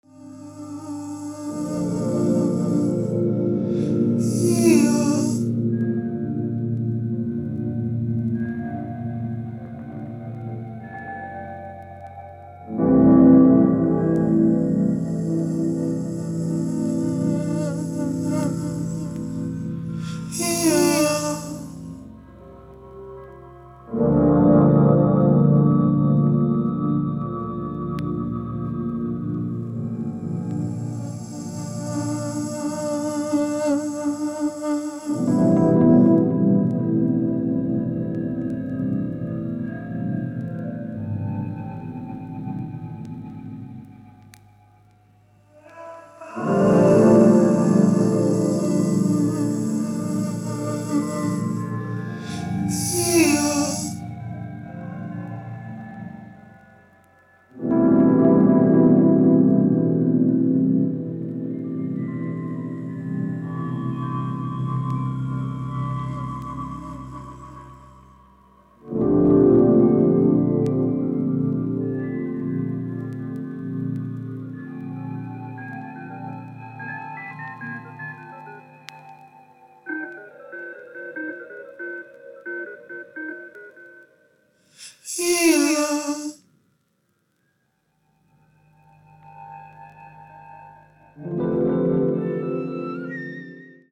Ambient, Experimental